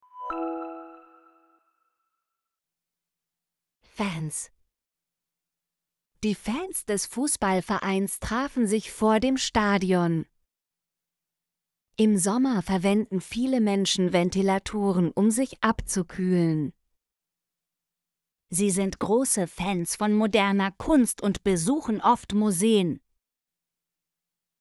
fans - Example Sentences & Pronunciation, German Frequency List